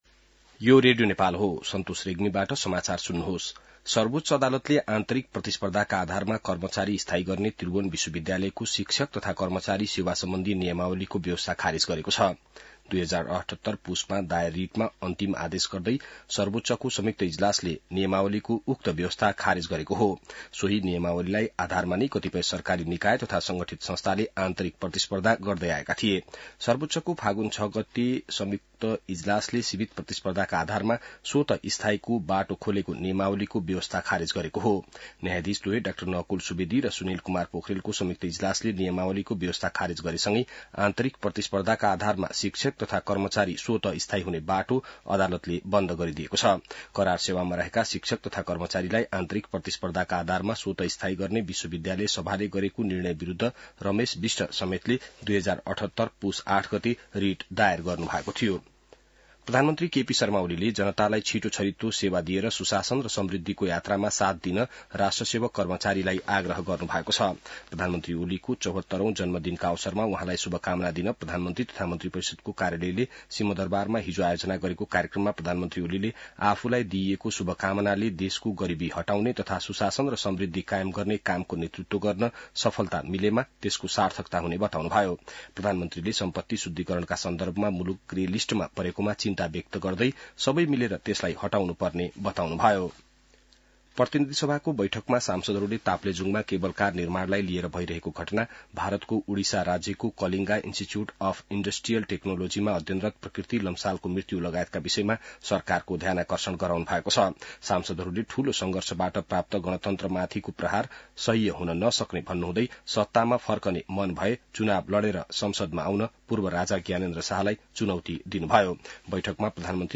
बिहान ६ बजेको नेपाली समाचार : १३ फागुन , २०८१